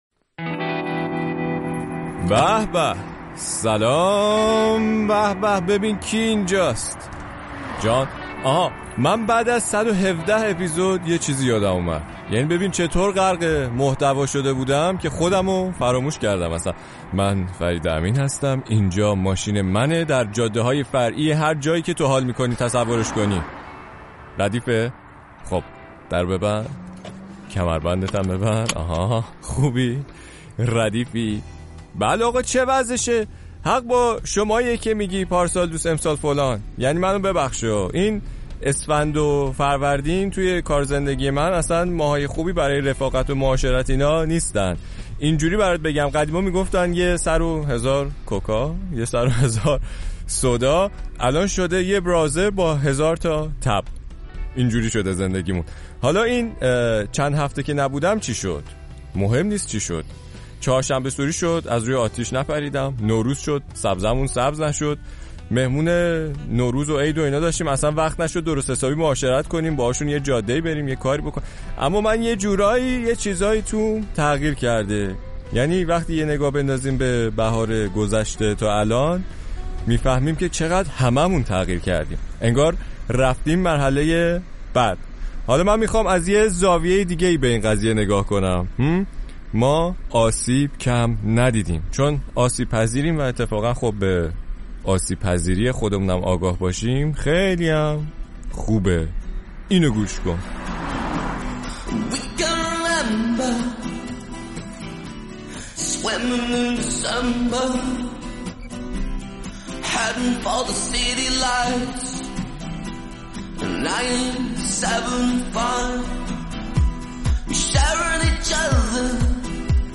پادکست موسیقی